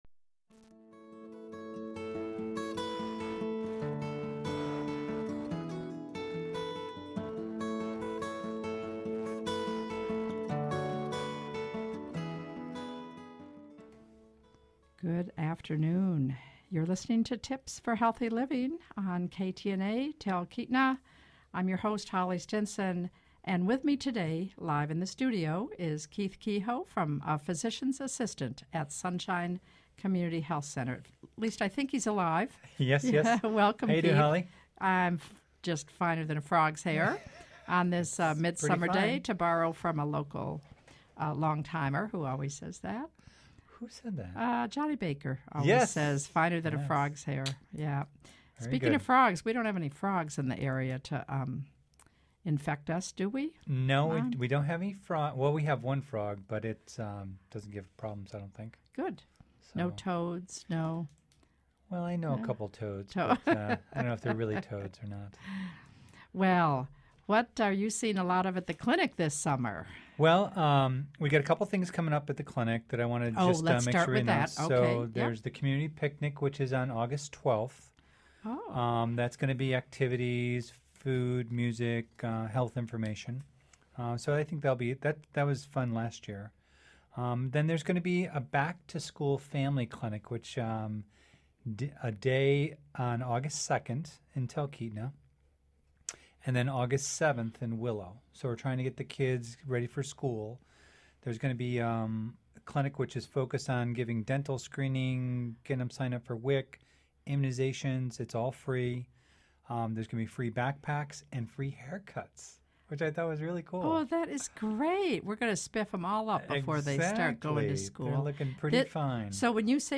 A live 15-minute conversation about health and wellness from health care providers in our communities.